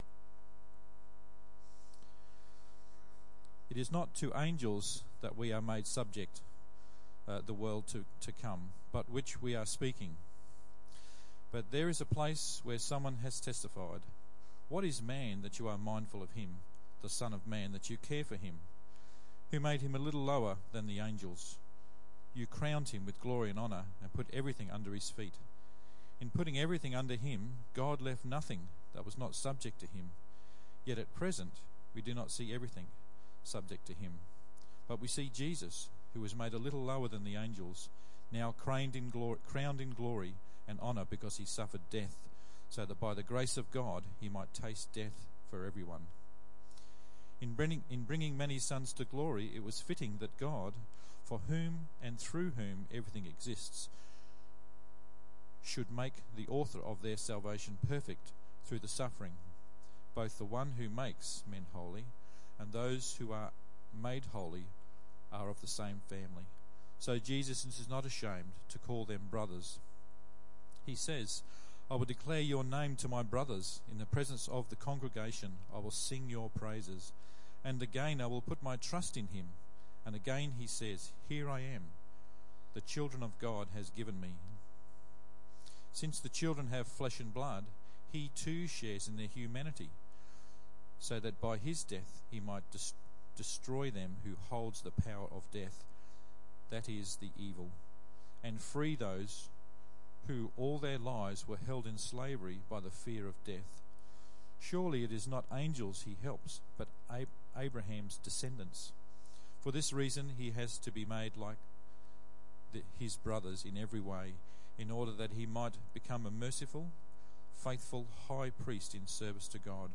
Hebrews 2:5-18 Tagged with Sunday Morning